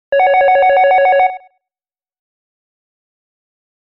FEATURED BUSINESS RINGS